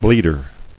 [bleed·er]